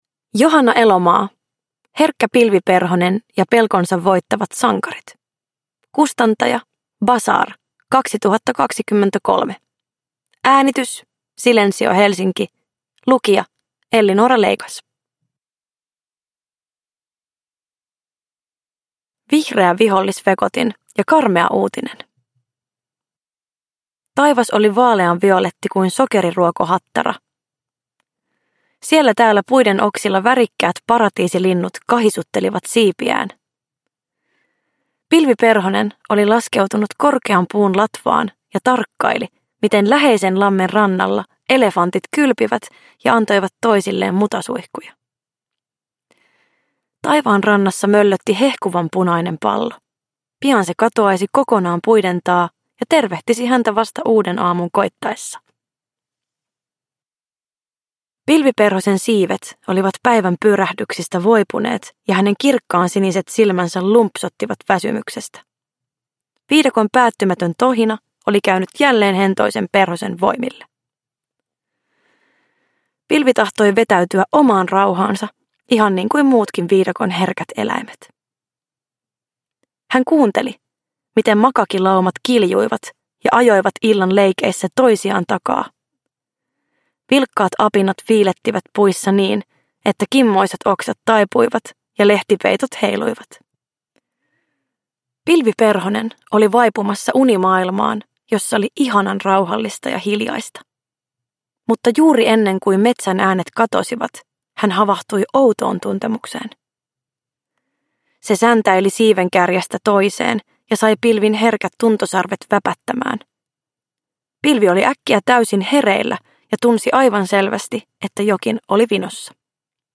Herkkä Pilvi Perhonen ja pelkonsa voittavat sankarit – Ljudbok – Laddas ner